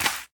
Minecraft Version Minecraft Version snapshot Latest Release | Latest Snapshot snapshot / assets / minecraft / sounds / block / sweet_berry_bush / place6.ogg Compare With Compare With Latest Release | Latest Snapshot